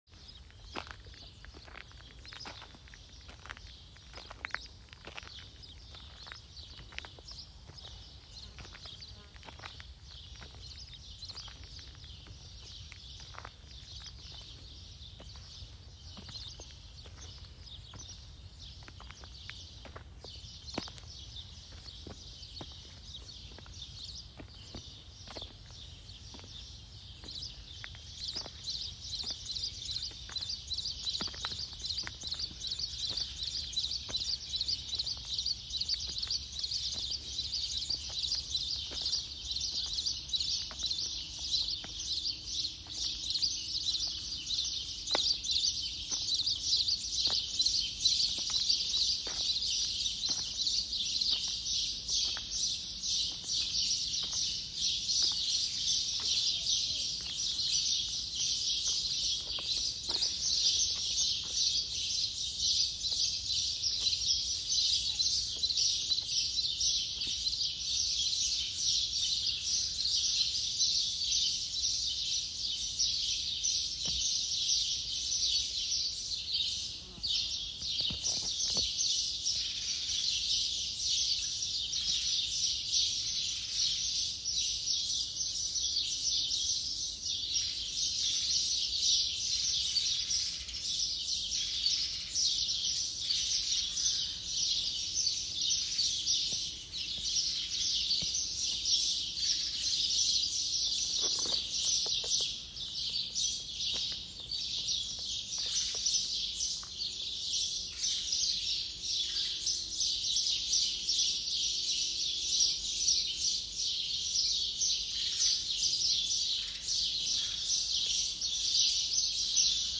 Walking back into Hagar Qim, neolithic temples
Footsteps on the stony path, hundreds of sparrows, the sound changing when entering under the canopy that covers the temples, other birds, insects buzzing.